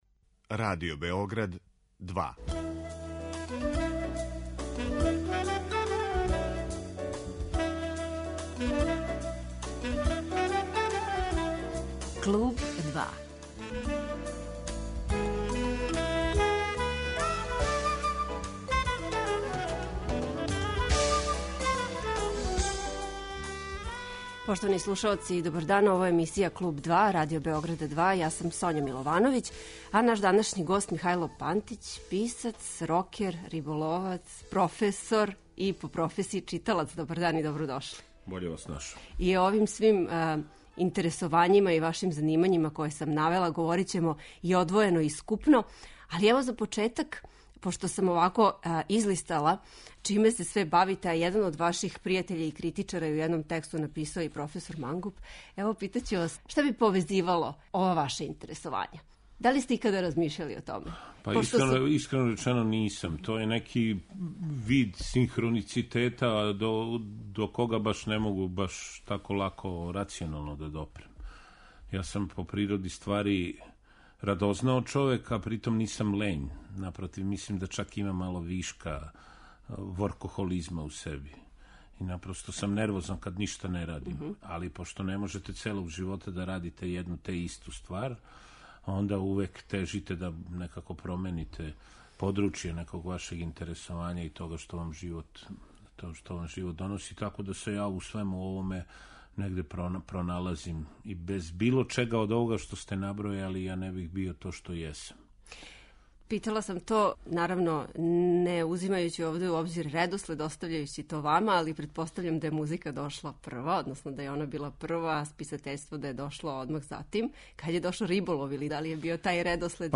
Гост 'Клуба 2' је Михајло Пантић